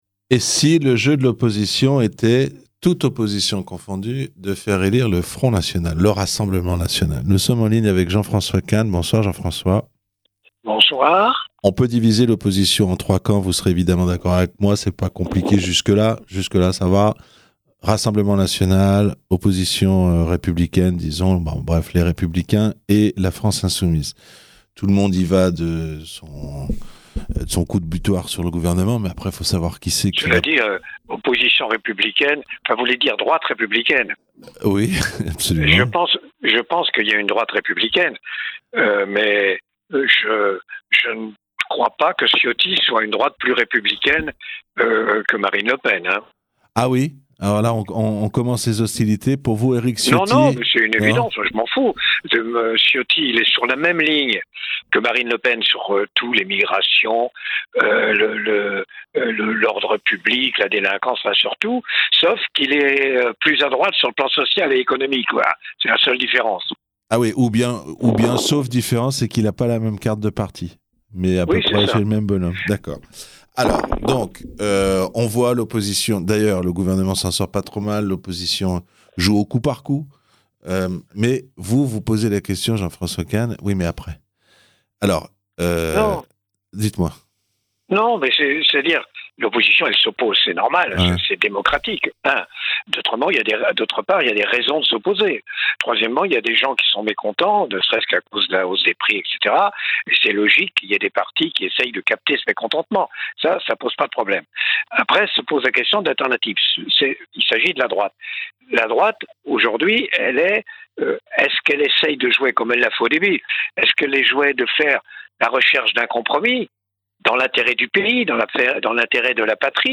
L'analyse de Jean François Kahn